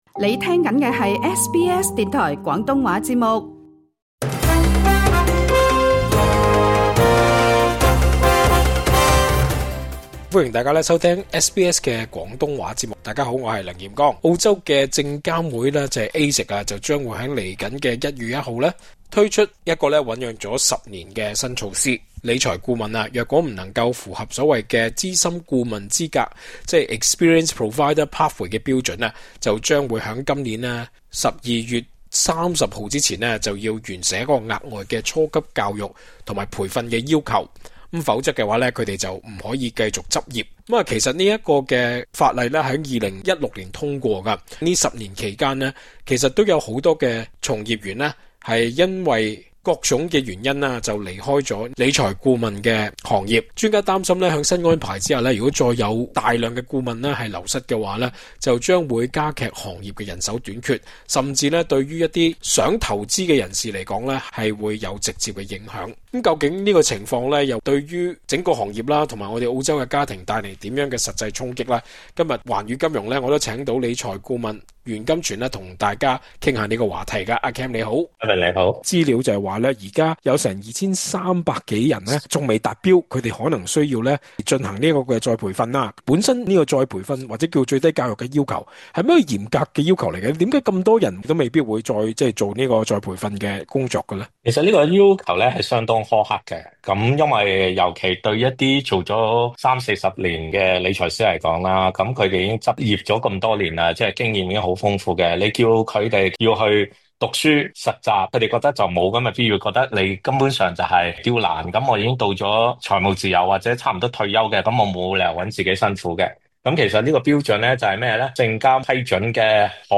詳盡訪問： LISTEN TO 【15%理財顧問明年料流失】投資服務更貴或加劇窮人越窮困境？